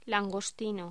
Locución: Langostino
voz